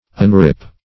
Unrip \Un*rip"\